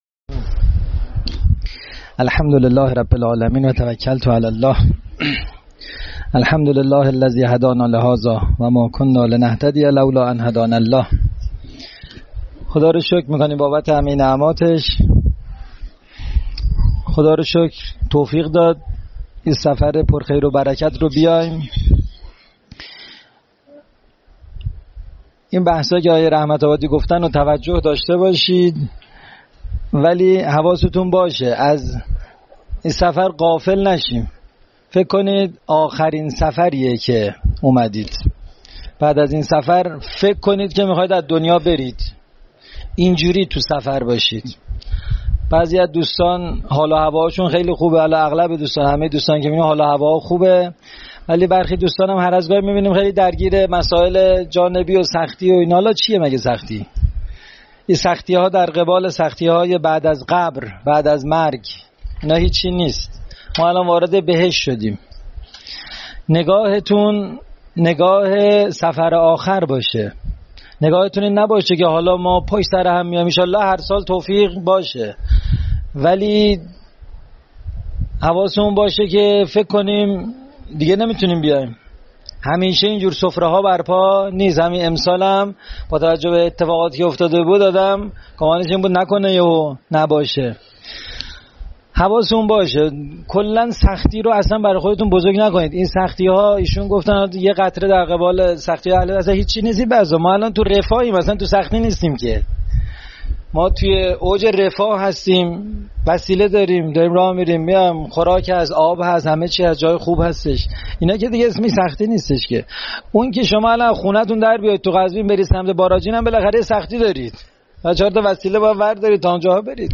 سخنرانی
سفر اربعین 1404 کاروان معارفی تمسک